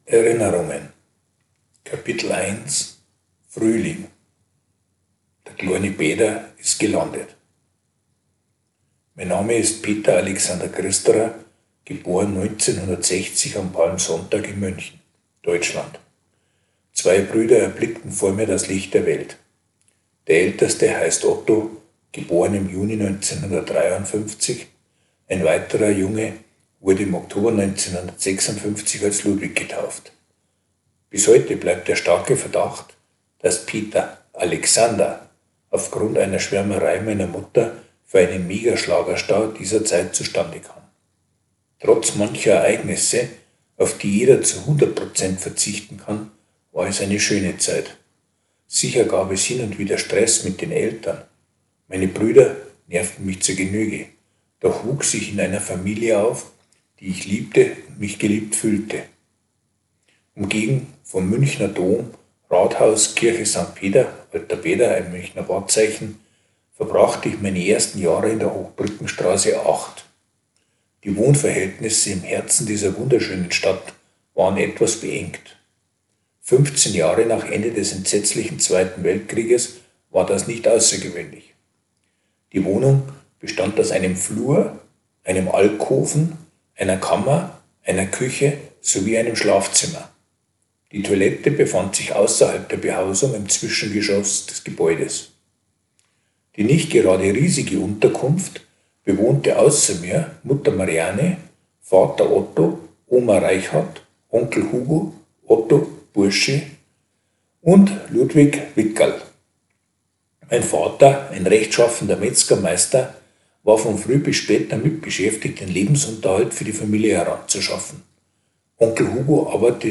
Beitrag vorlesen (3:26 Minuten)